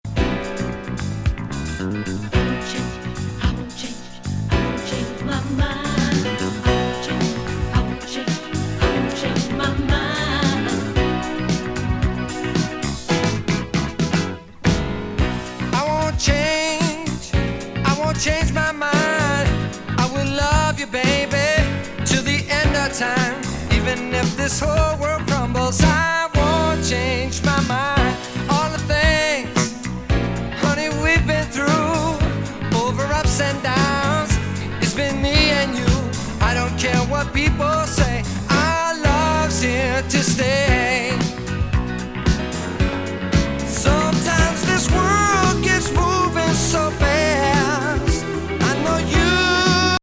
AOR